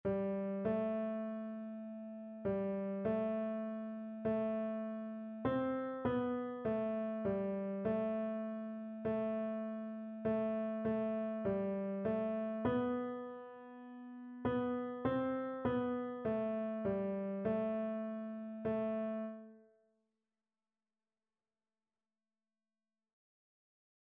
4/4 (View more 4/4 Music)
Piano  (View more Beginners Piano Music)
Classical (View more Classical Piano Music)